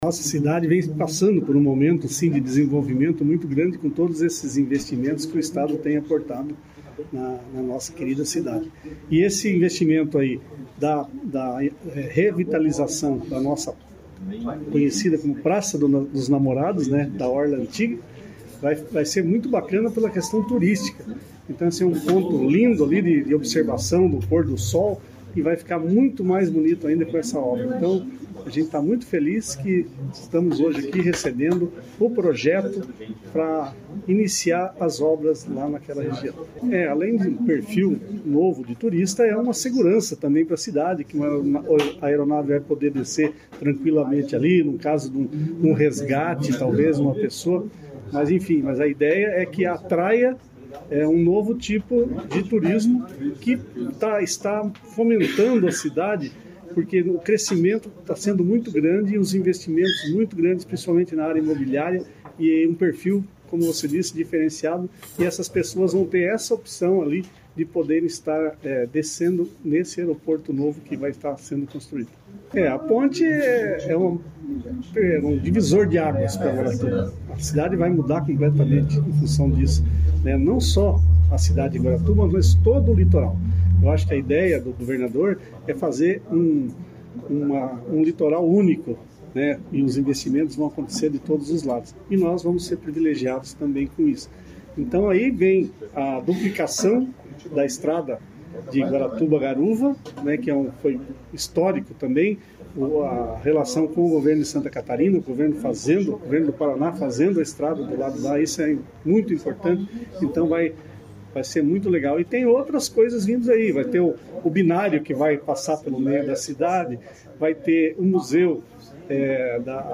Sonora do prefeito de Guaratuba, Mauricio Lense, sobre os novos investimentos na cidade